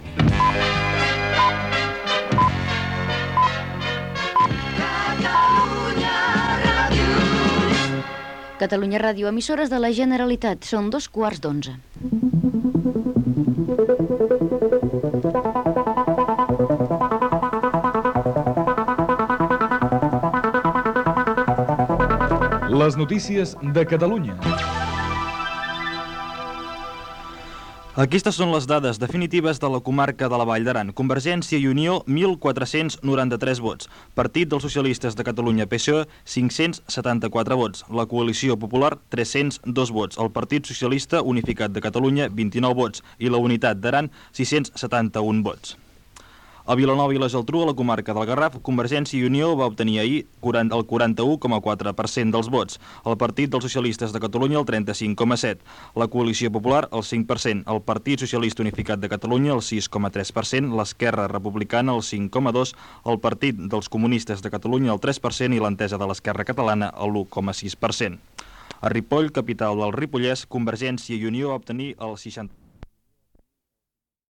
Indicatiu de l'emissora. Dades del resultat de les eleccions al Parlament de Catalunya a La Vall d'Aran i Vilanova i la Geltrú
Informatiu
FM